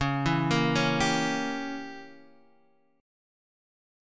C#dim7 chord